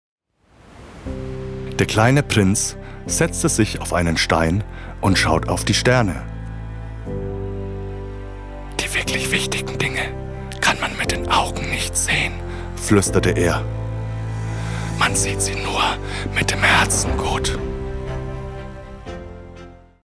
Hörbücher: „Klassische Literatur“